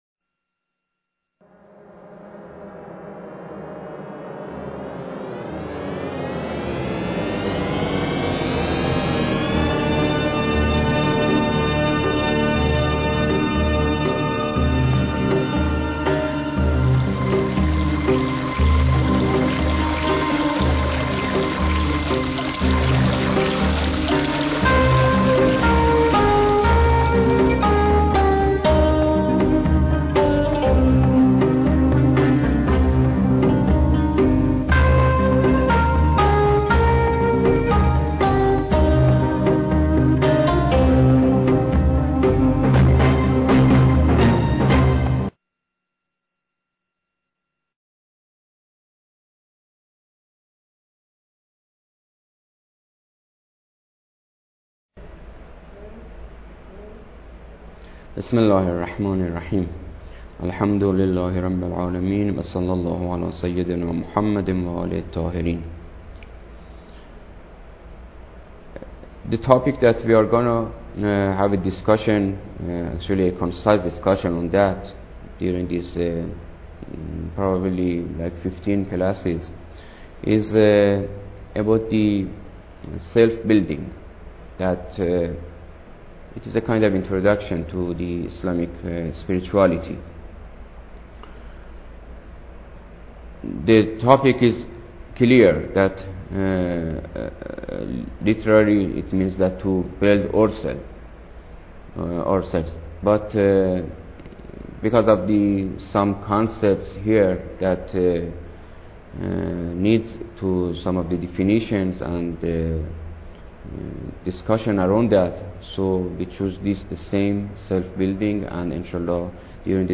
Lecture_1